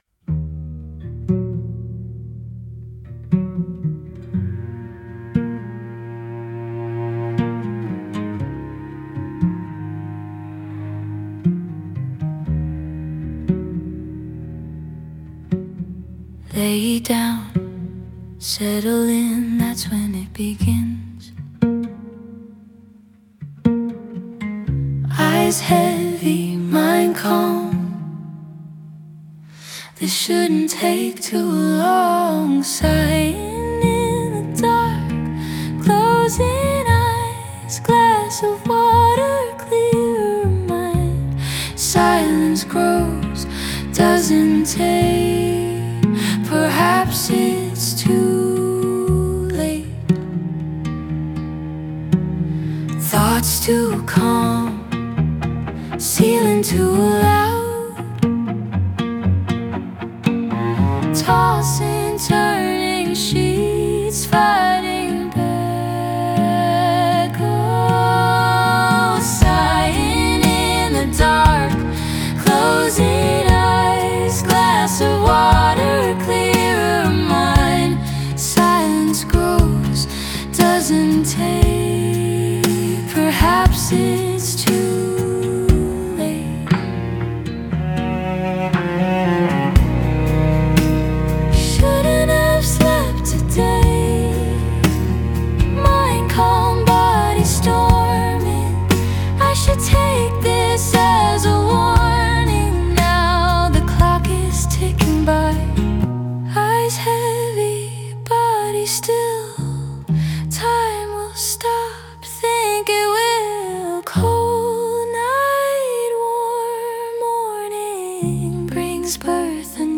guitar
cello